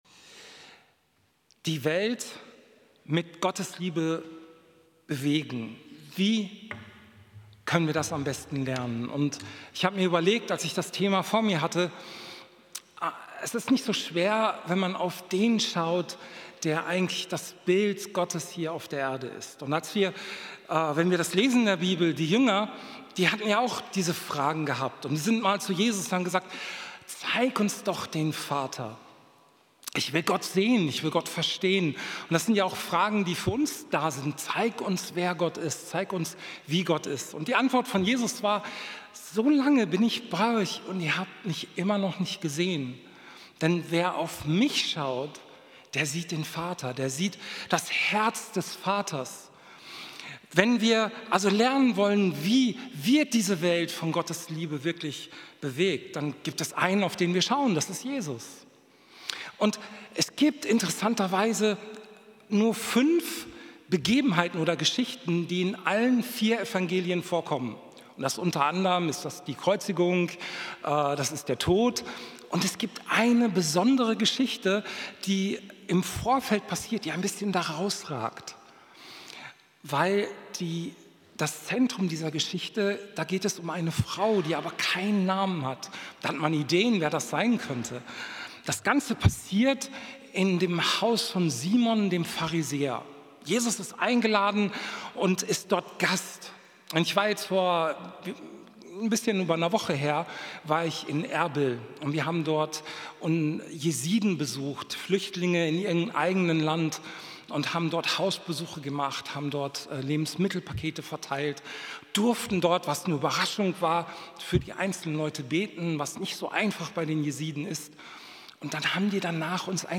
Predigt vom 12.10.2025 Spätgottesdienst